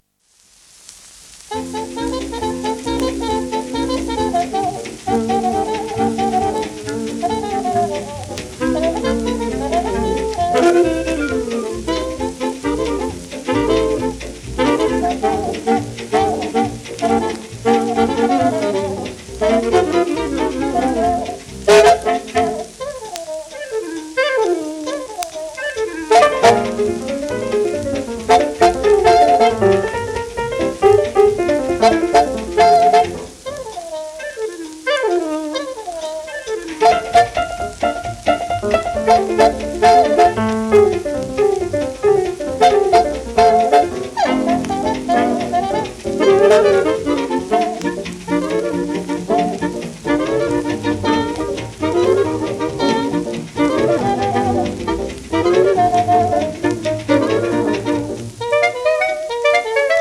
シェルマン アートワークスのSPレコード